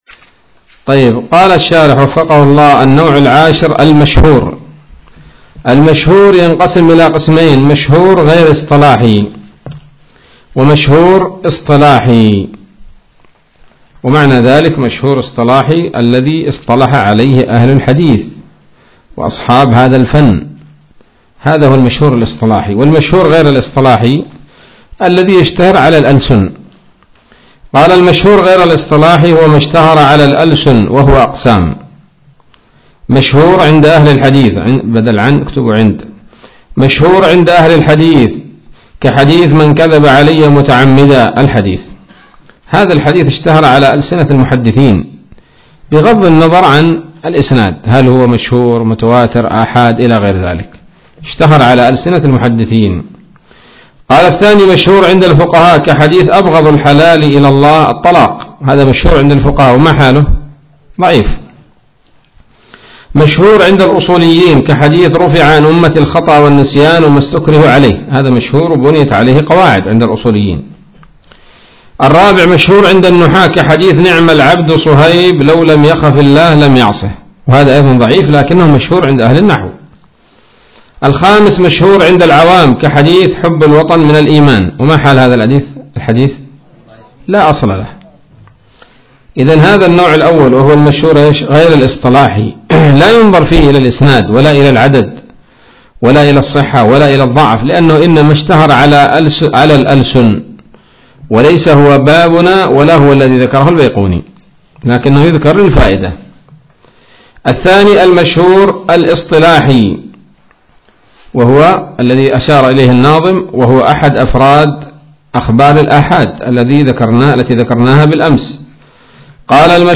الدرس السادس عشر من الفتوحات القيومية في شرح البيقونية [1444هـ]